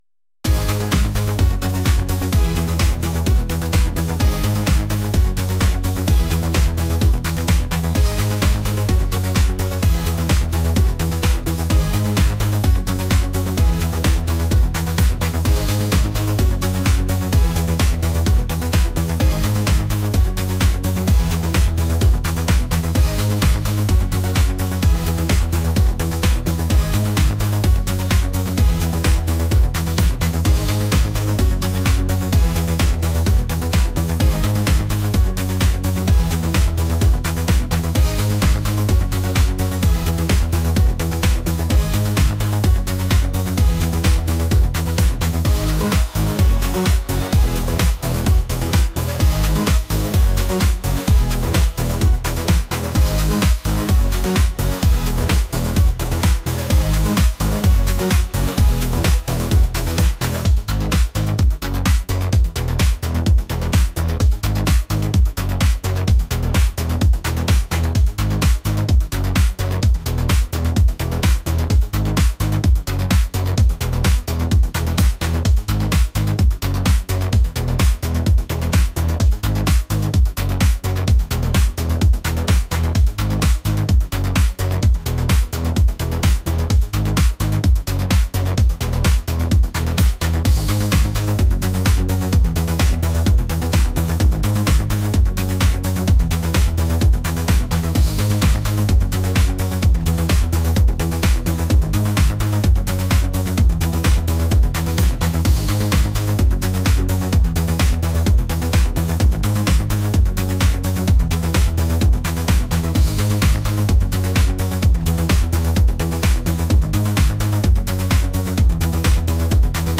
energetic | pop